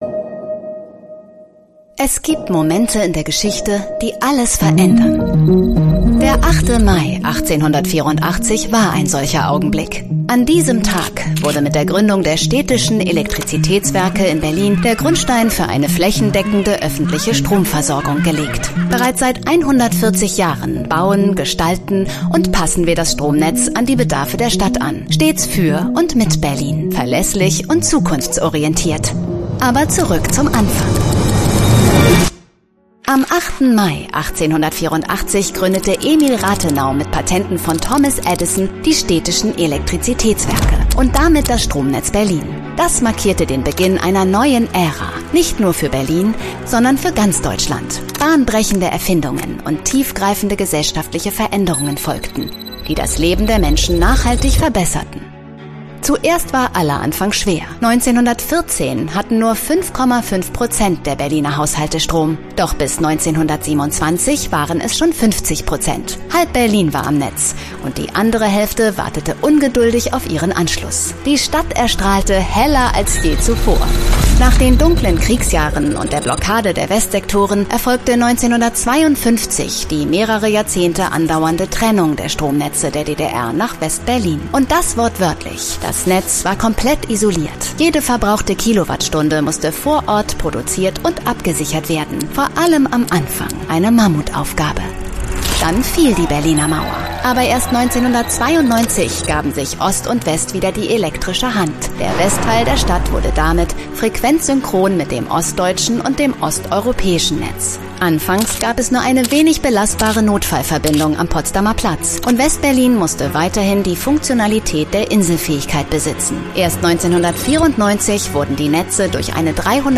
Comercial, Accesible, Cálida, Suave, Empresarial
Corporativo